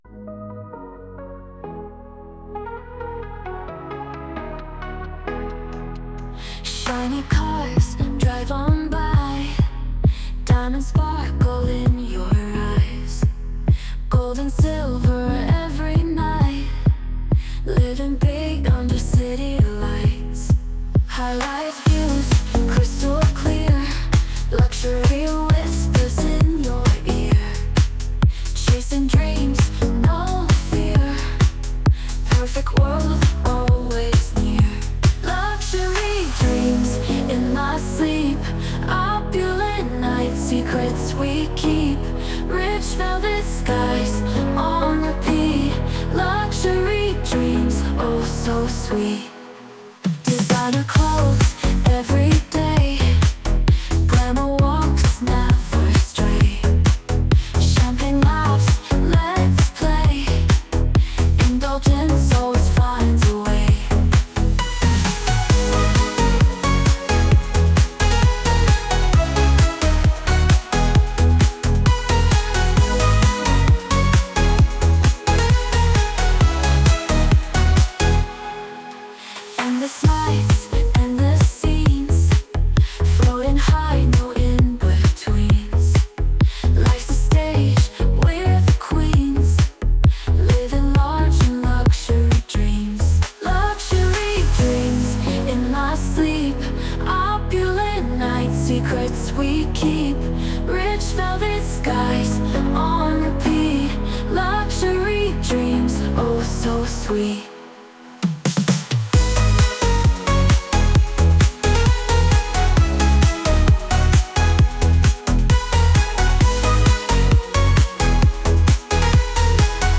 Music EDM Music